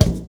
NY 15 BD.wav